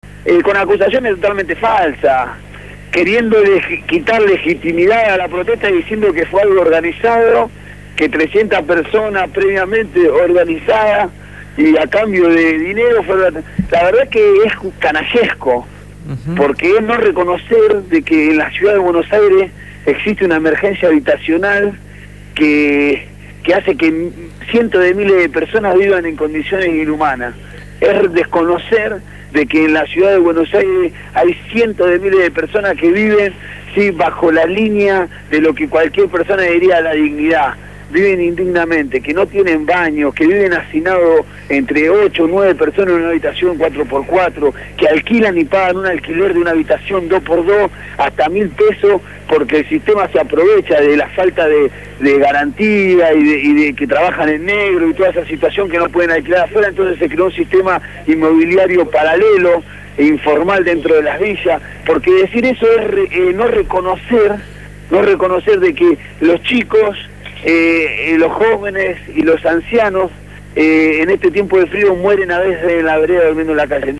En conversación telefónica